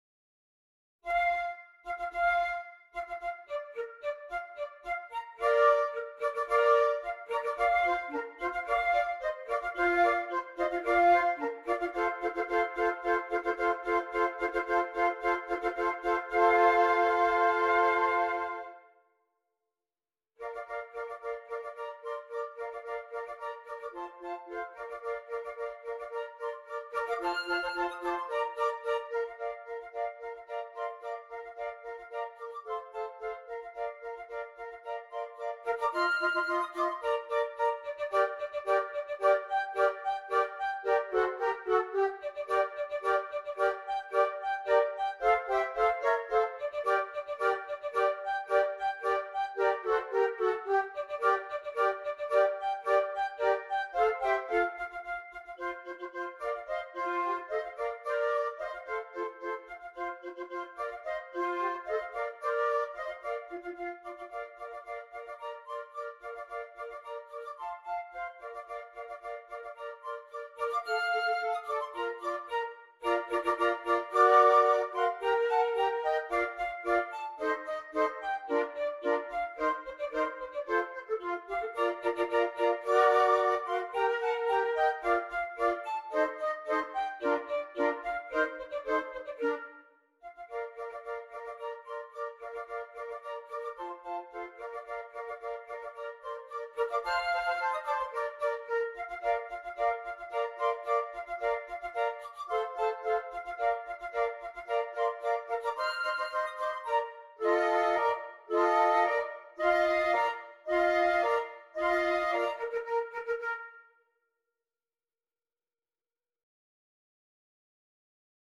4 Flutes